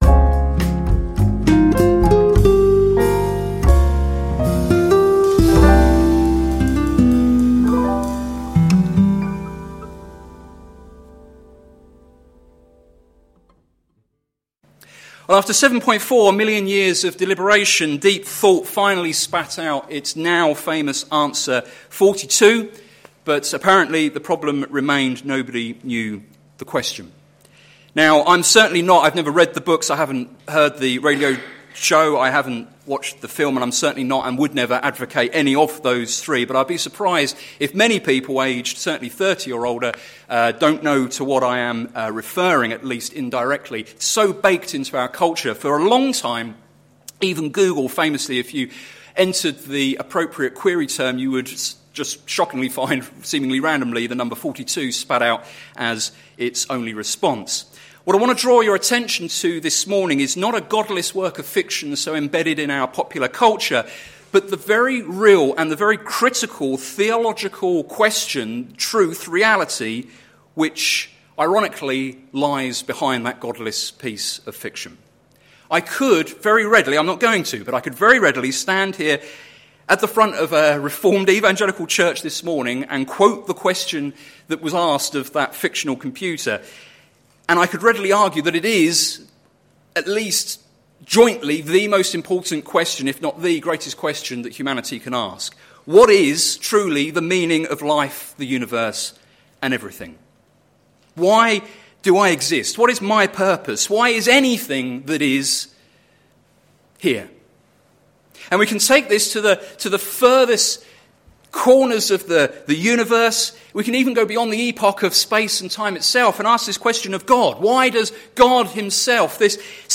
Sermon Series - Year Verses - plfc (Pound Lane Free Church, Isleham, Cambridgeshire)